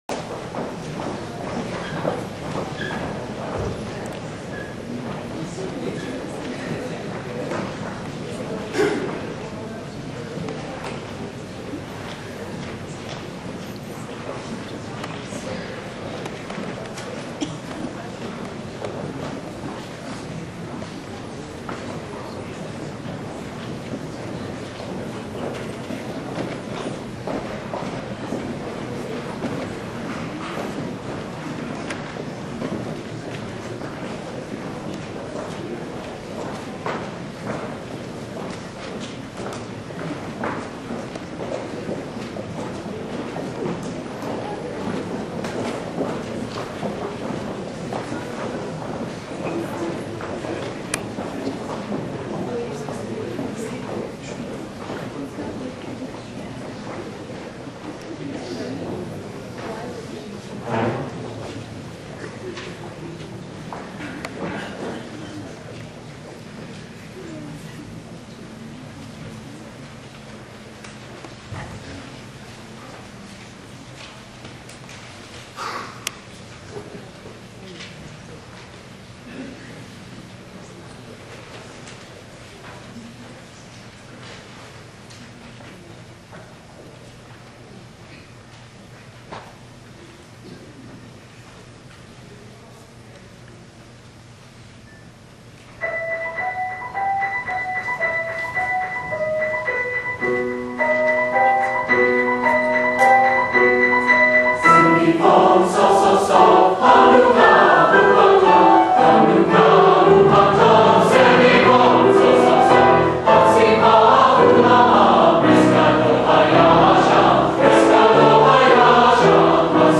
CALTECH GLEE CLUBS HOLIDAY CONCERT Saturday, December 2, 2006
glee2006both.wma